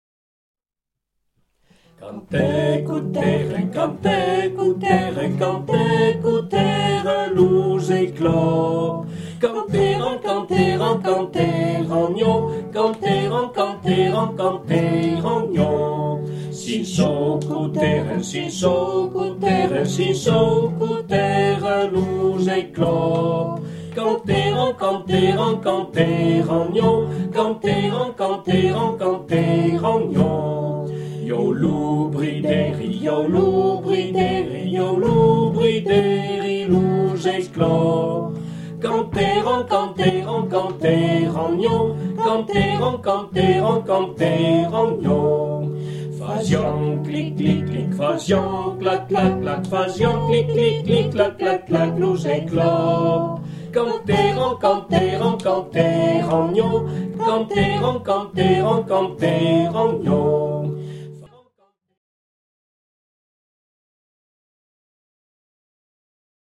C'est un "CD de travail" : les vrais musiciens pourront s'atteler à trouver des accompagnements, ils seront les bienvenus.)